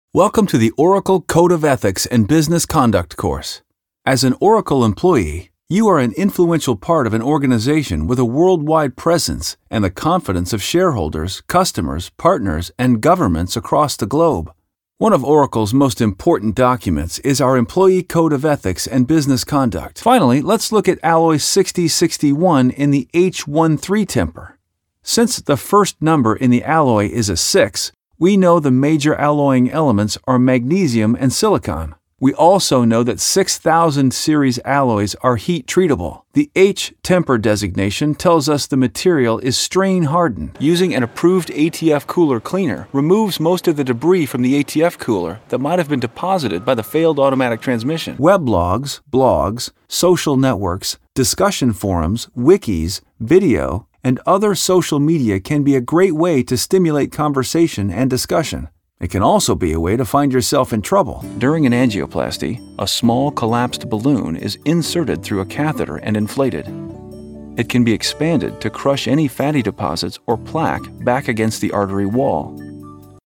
Guy Next Door, Trustworthy, Honest, Intelligent, Strong, Believable, Real, Energetic, Conversational, Tech Savvy
Sprechprobe: eLearning (Muttersprache):